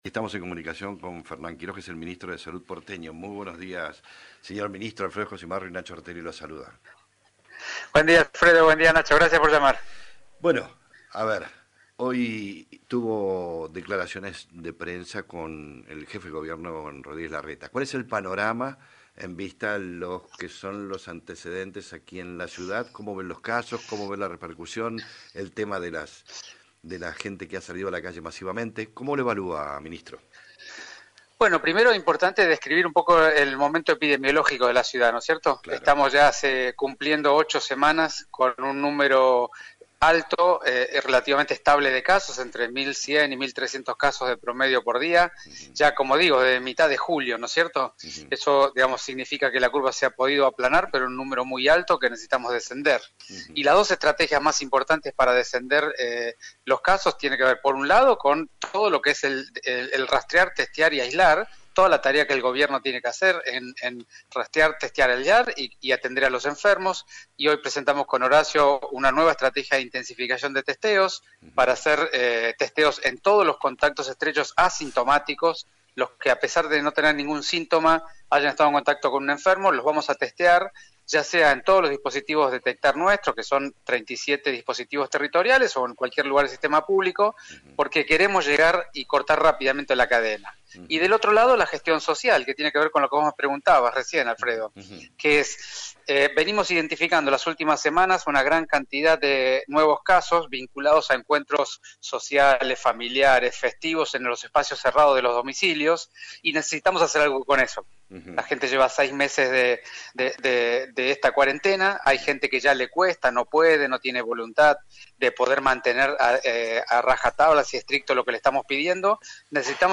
Fernán Quiros, Ministro de Salud de la Ciudad de Buenos Aires, dialogó en Alguien Tiene que Decirlo sobre la evolución de la curva de contagios de Covid-19 en el país y se refirió a las aperturas de los bares y restaurantes: “hay que aprender a realizar salidas seguras y estar en el espacio público”.